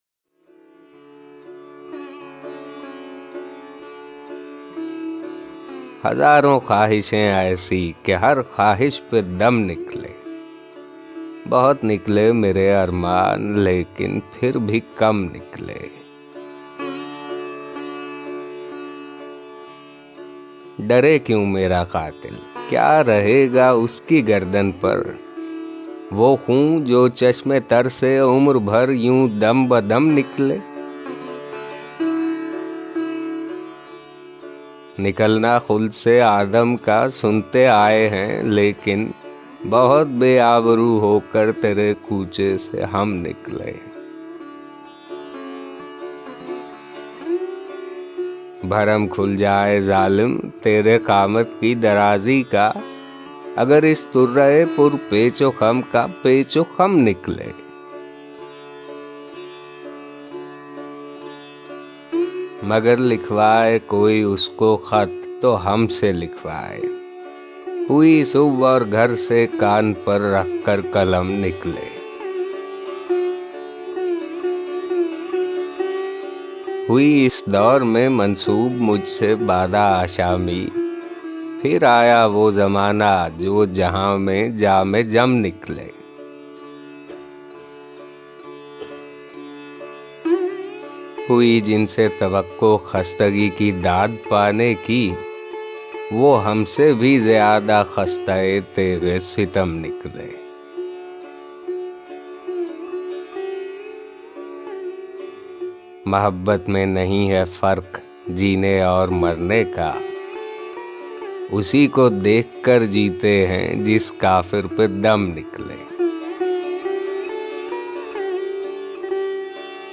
Mirza Ghalib, Audiobooks